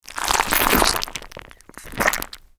slime_squish.mp3